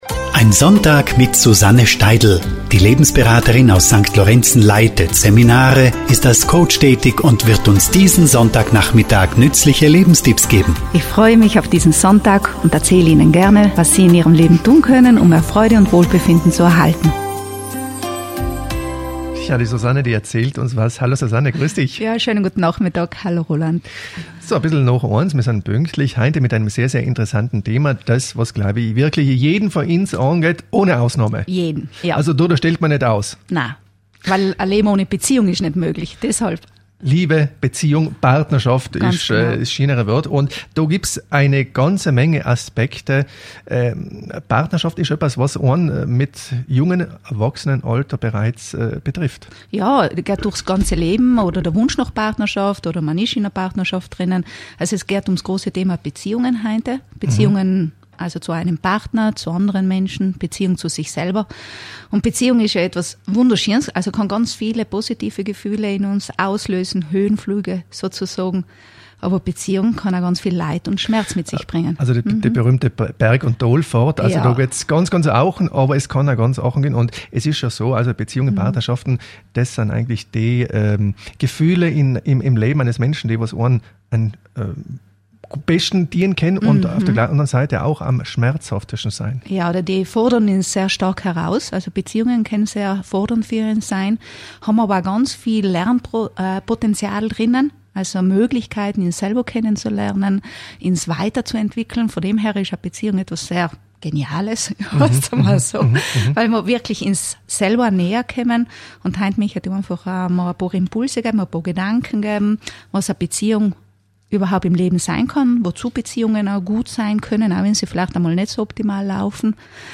In dieser Radiosendung spreche ich zum Thema: Liebe, Beziehung und Partnerschaft.